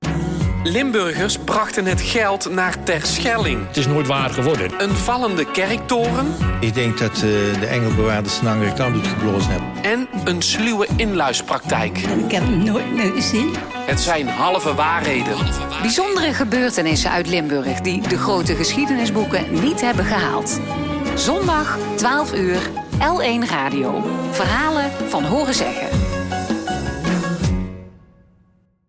radioprogramma-reeks
promo Van horen zeggen, aflevering Halve waarheden
De geluidsafwerking is mooi en de betrokkenheid van luisteraars die zelf kleine